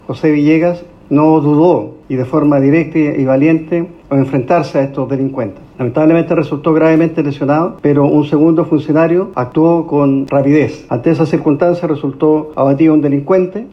En ese sentido, el General Director de Carabineros, Marcelo Araya, destacó la labor realizada por el sargento y su compañero, que terminó con una encerrona frustrada y uno de los delincuentes abatidos.